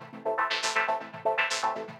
SaS_MovingPad04_120-A.wav